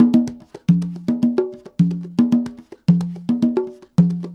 Funk Master Conga 02.wav